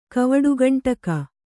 ♪ kavaḍugaṇṭaka